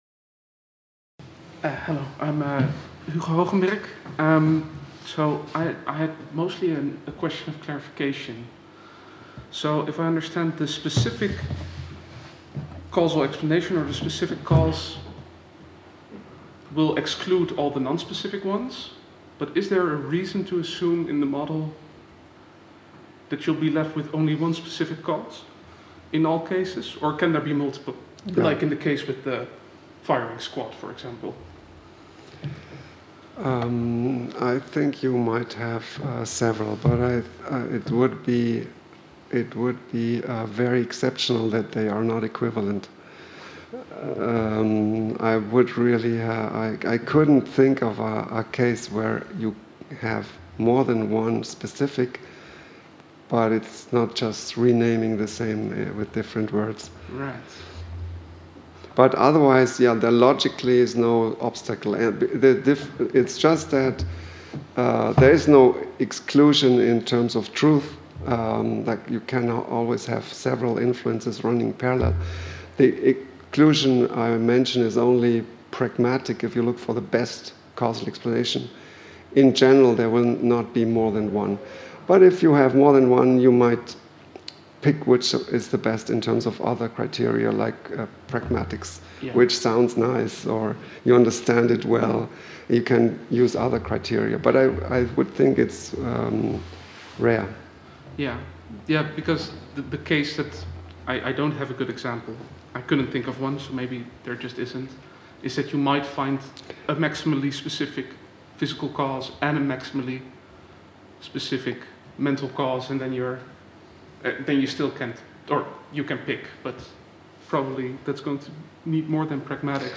Discussion (4) | Collège de France